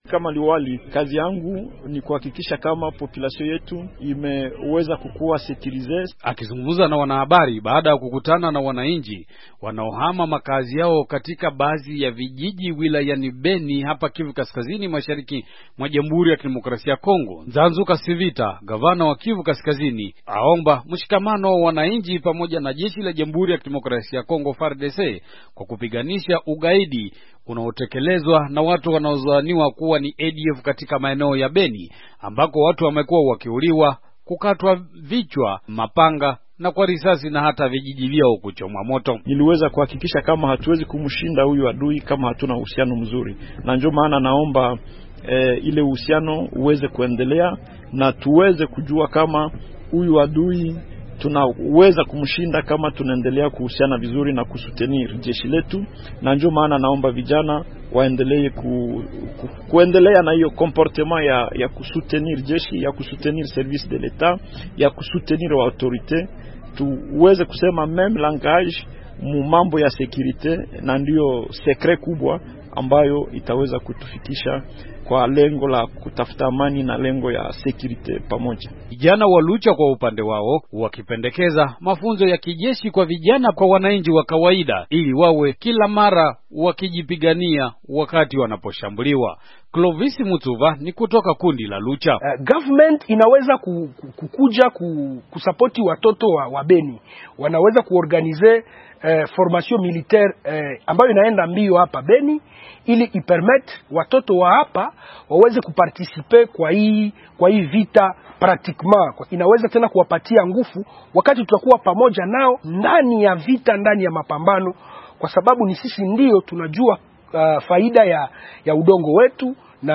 Habari